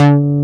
MKSBASS6.wav